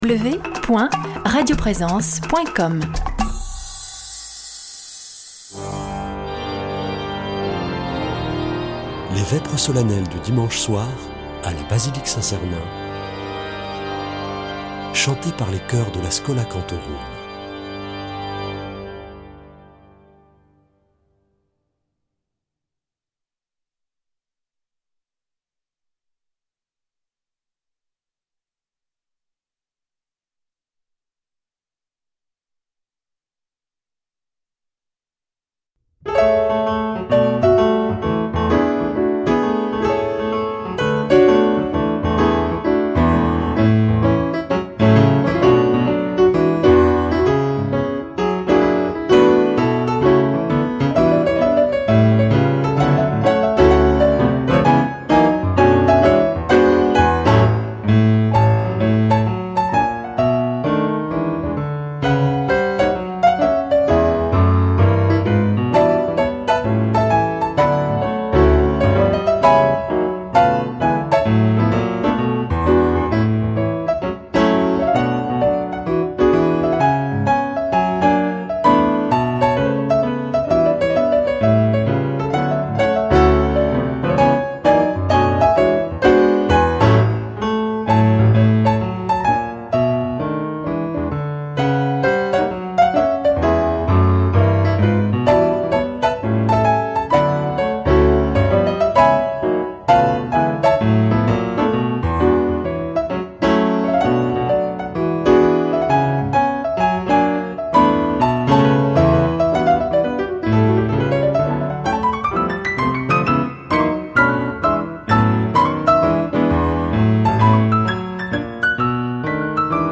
Vêpres de Saint Sernin du 02 juin
Une émission présentée par Schola Saint Sernin Chanteurs